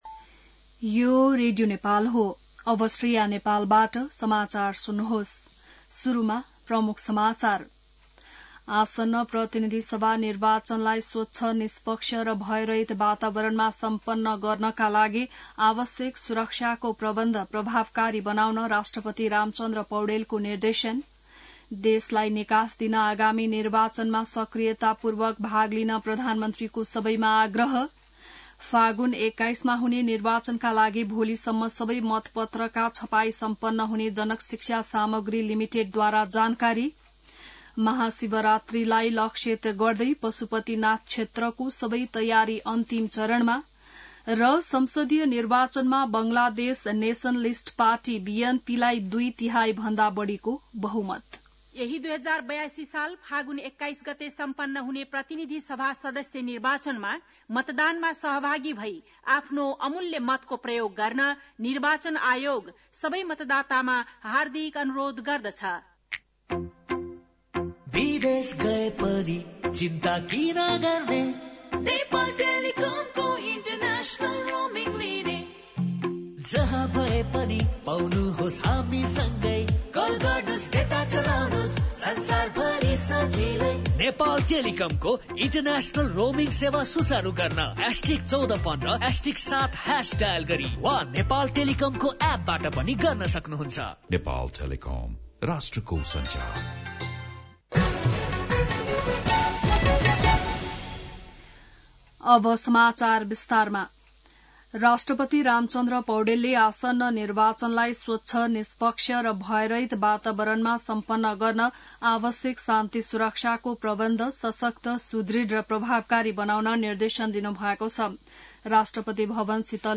बिहान ७ बजेको नेपाली समाचार : २ फागुन , २०८२